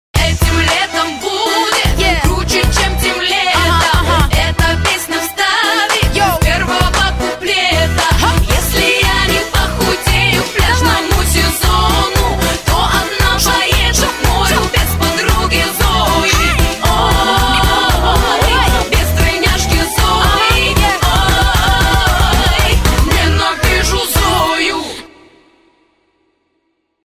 Рэп, Хип-Хоп, R'n'B [75]